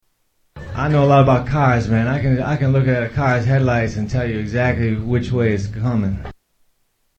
Category: Comedians   Right: Personal
Tags: Comedians Mitch Hedberg Sounds Mitchell Lee Hedberg Mitch Hedberg Clips Stand-up Comedian